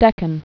(dĕkən)